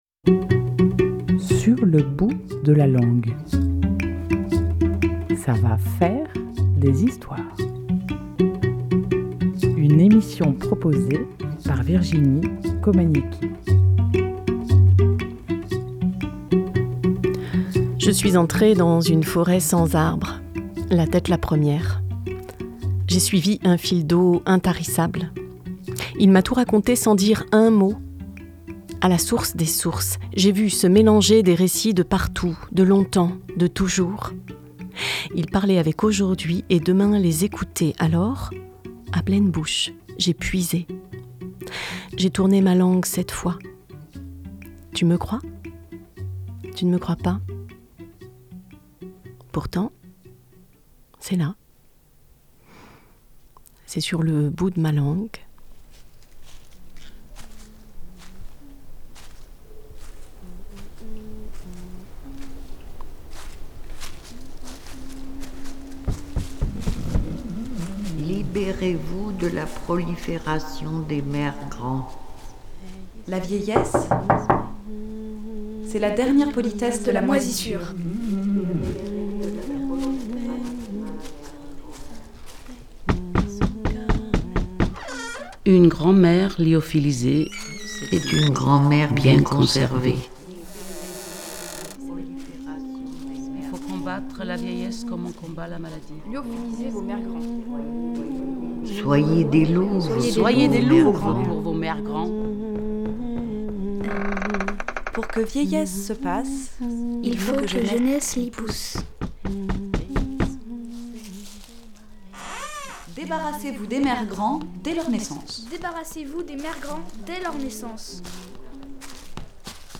Musiques et sons : Bande son crée pour le spectacle Rouge Mémère